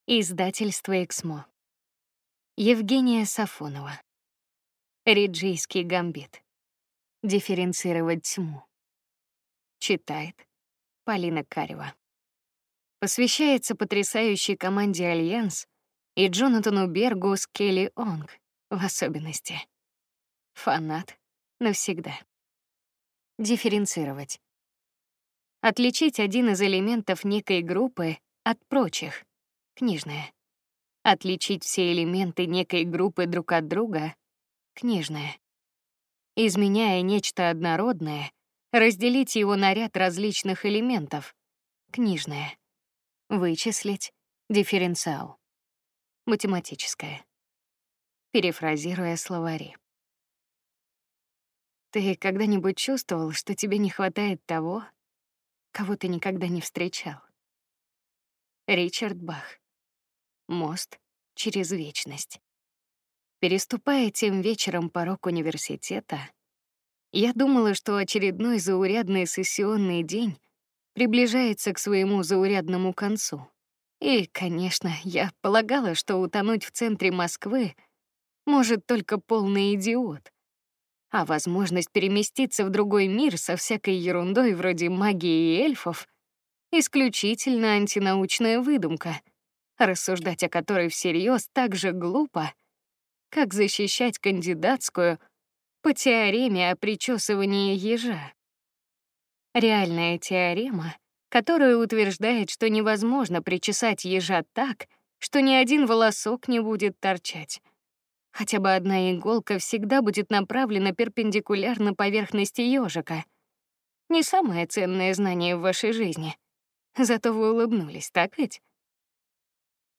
Аудиокнига Дифференцировать тьму | Библиотека аудиокниг
Прослушать и бесплатно скачать фрагмент аудиокниги